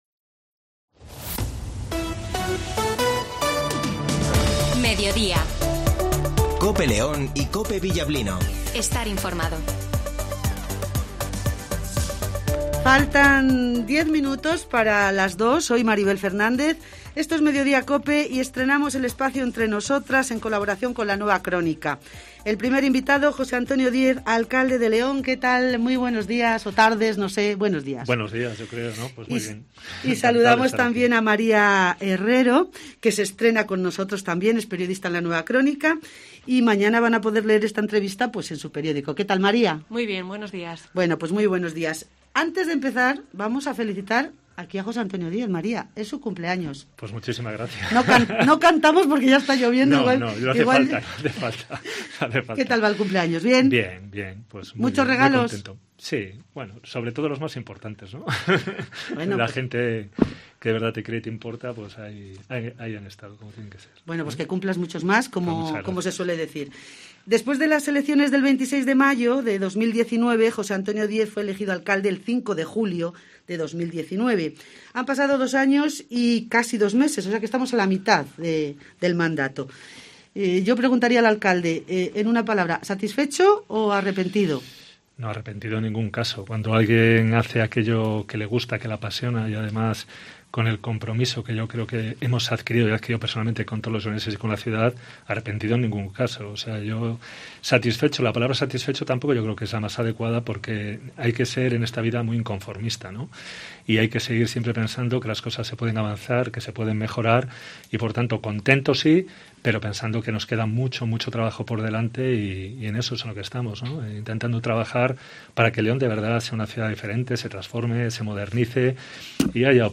Hoy en los estudios de COPE LEÓN, José Antonio Díez alcalde de León